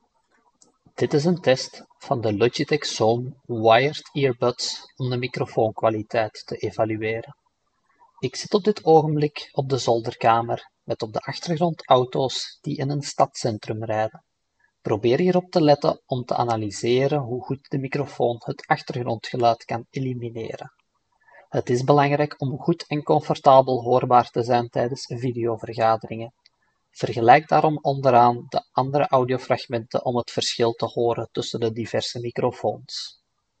You can use the following audio clips to assess the quality of the microphone and compare it with other Logitech models.